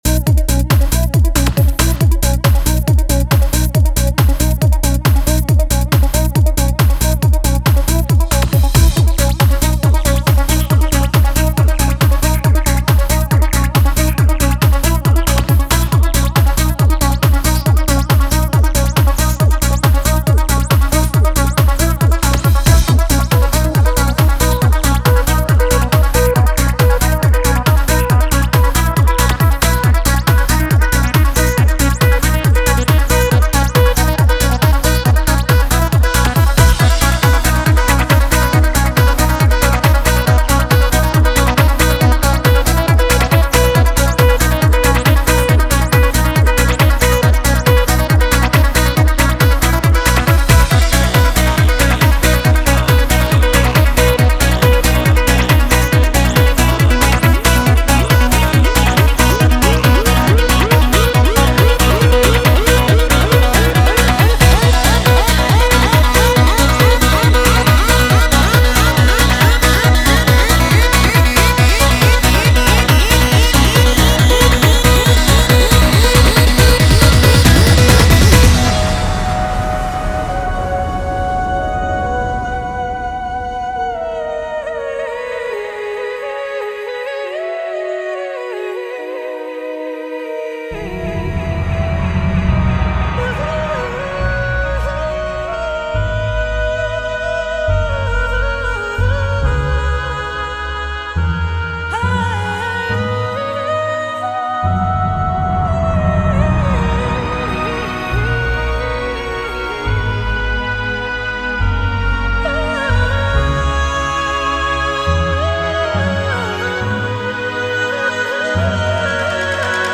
audio remasterizado